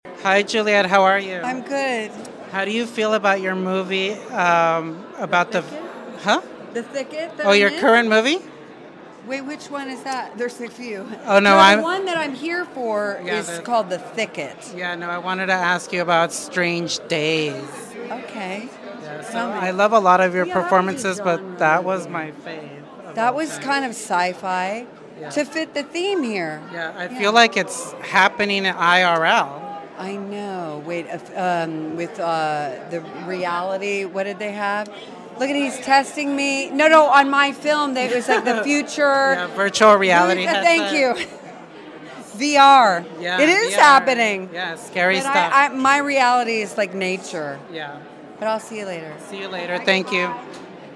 Juliette Lewis at Saturn Awards sound effects free download